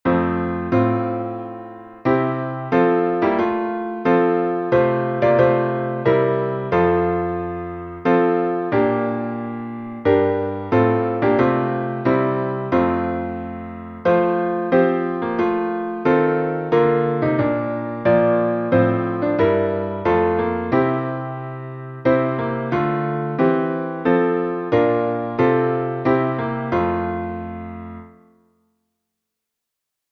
salmo_127B_instrumental.mp3